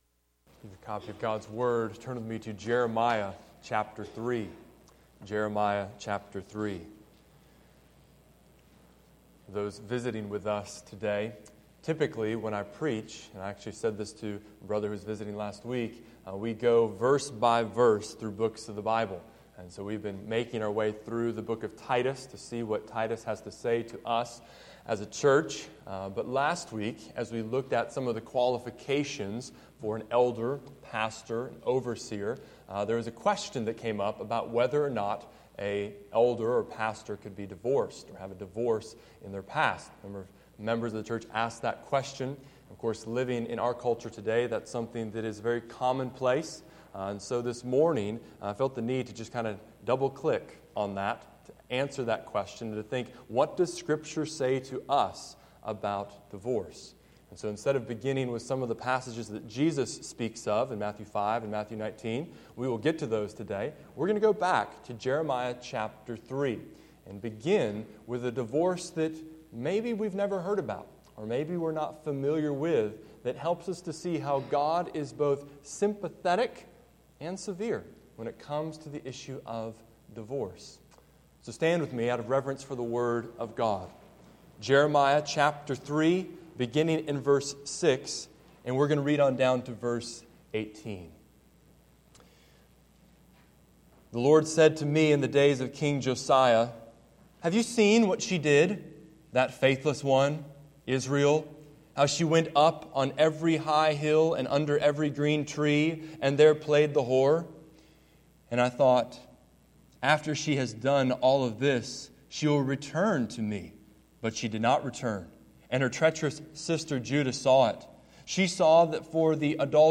In Sunday’s sermon (“What about divorce?“) I listed seven ways that Scripture speaks about divorce.